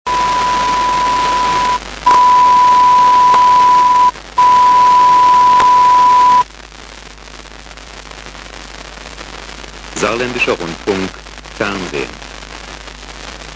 SR1 - Saar Testbild bei einer analogen Zuspielung in SIS